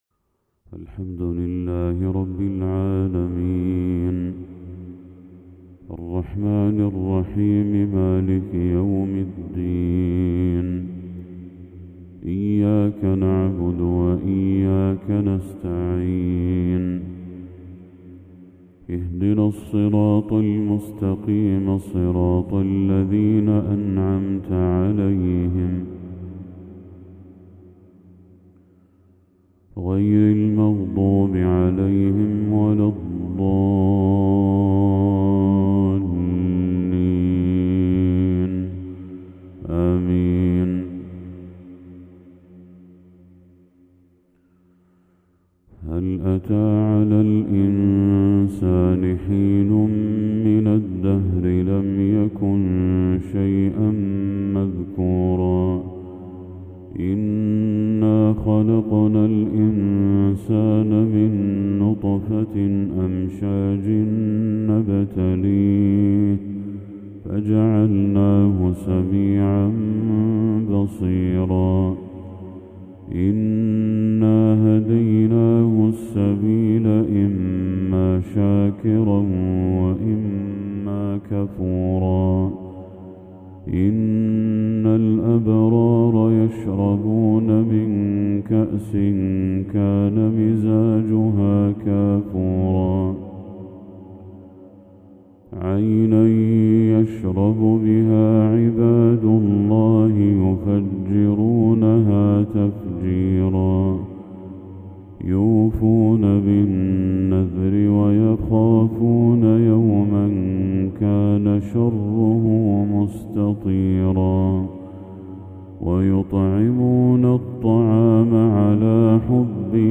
تلاوة لسورة الانسان للشيخ بدر التركي | فجر 3 محرم 1446هـ > 1446هـ > تلاوات الشيخ بدر التركي > المزيد - تلاوات الحرمين